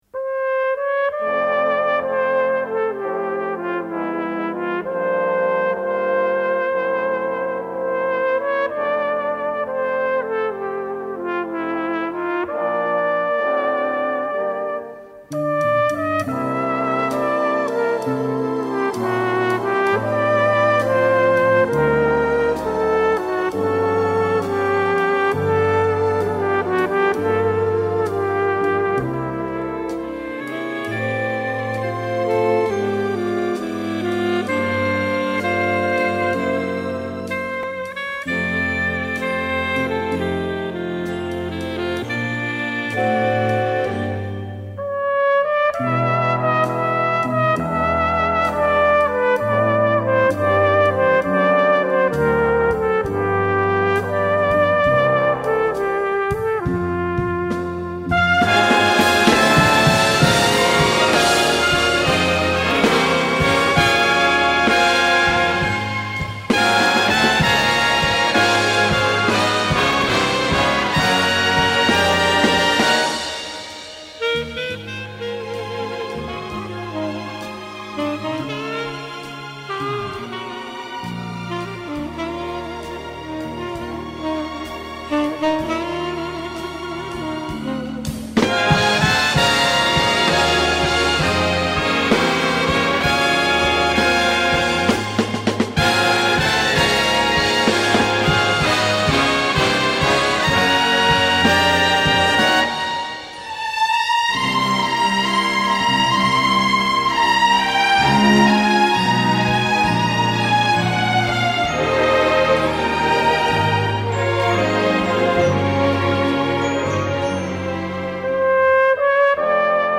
played in a pastiche style